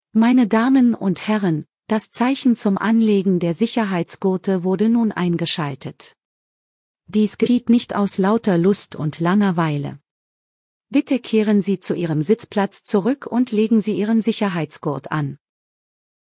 FastenSeatbelt.ogg